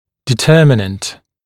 [dɪ’tɜːmɪnənt][ди’тё:минэнт]детерминант, определяющий фактор; определяющий, решающий